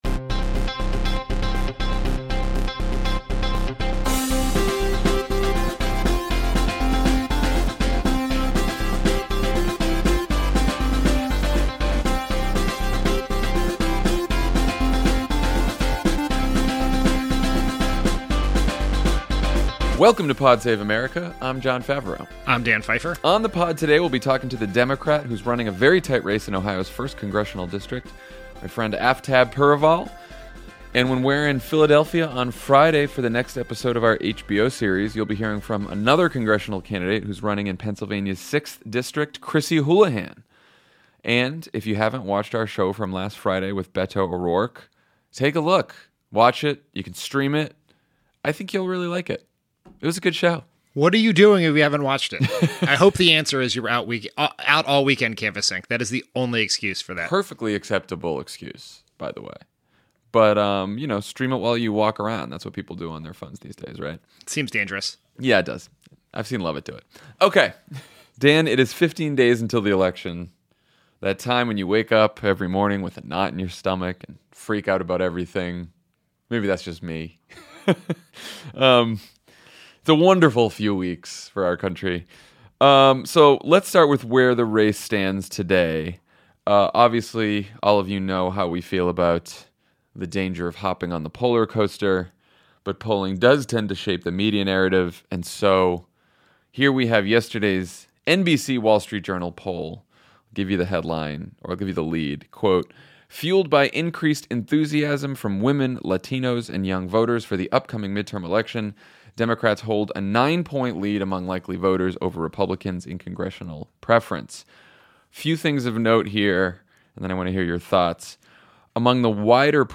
With 15 days to go, Jon and Dan give a midterm update, Trump uses caravans and voter fraud to scare people, Democrats try to flip state legislatures, and the Trump Administration wants to define transgender Americans out of existence. Then Democratic Congressional candidate Aftab Pureval talks to Jon about his race to flip the Ohio 1st, and why we need a new generation of leaders in Washington.